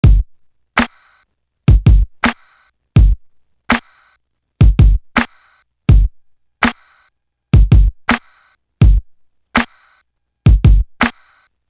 Carved In Stone Drum.wav